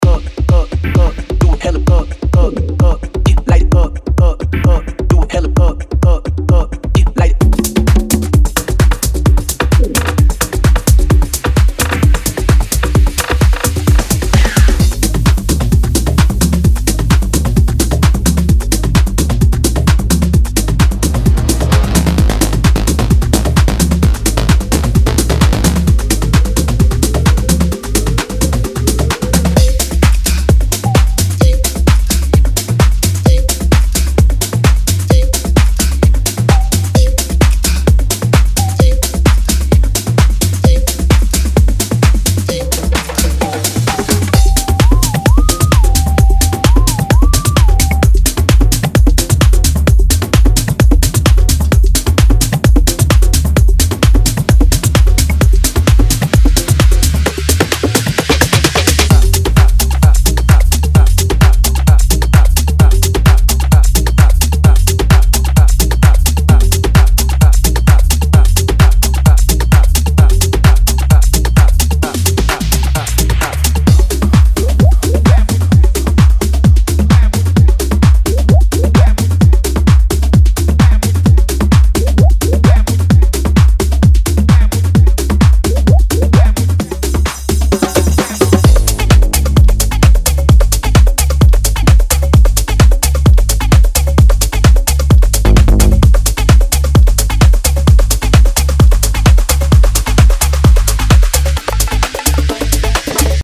Type: Samples